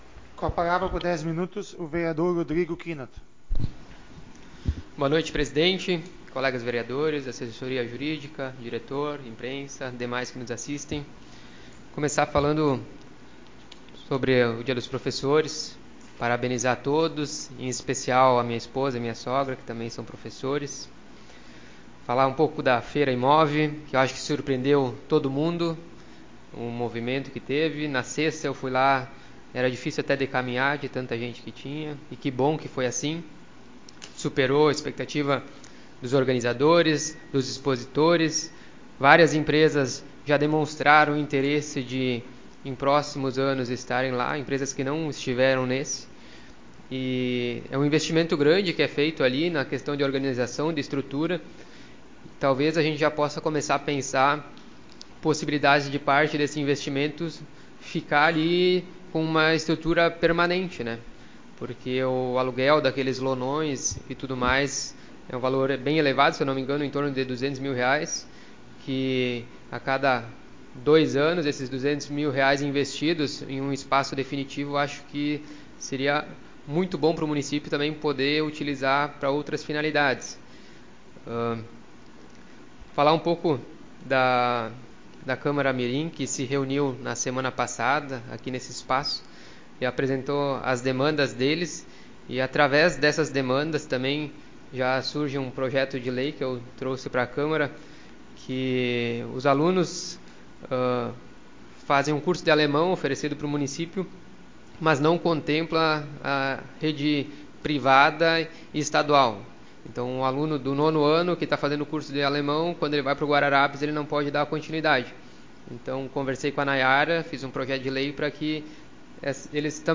Modalidade: Áudio das Sessões Vereadores